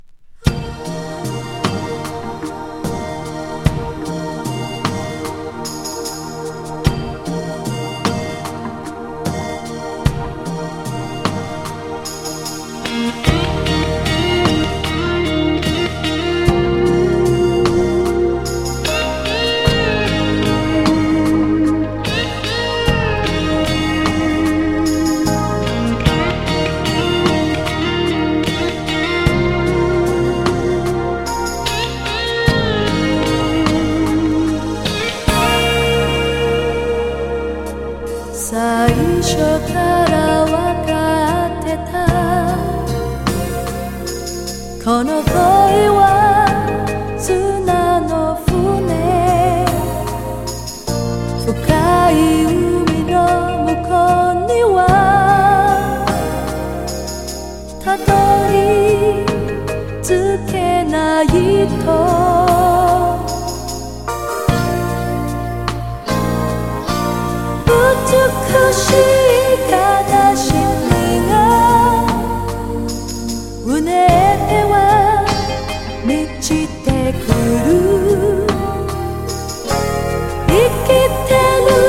アジアン・ニューエイジ・トロトロ・メロウ・日本語歌謡♥